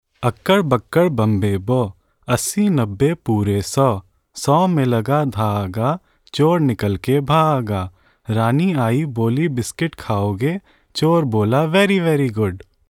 Nursery Rhymes